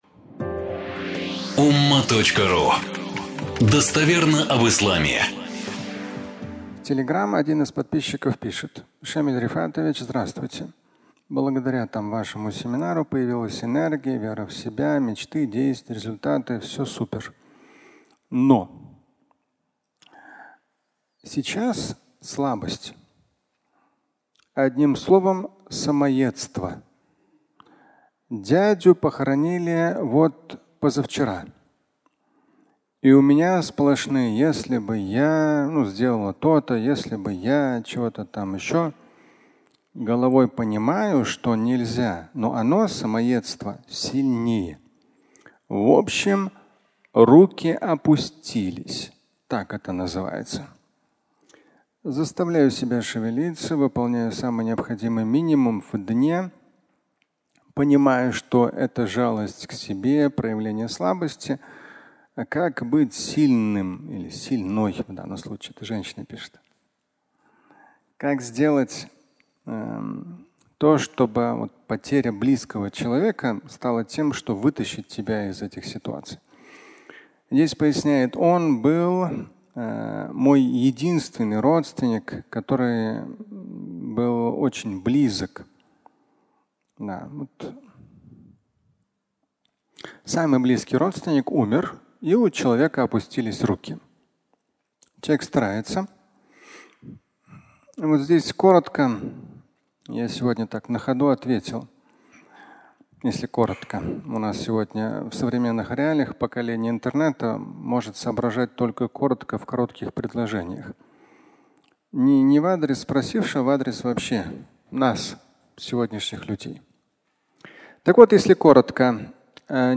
Опустились руки (аудиолекция)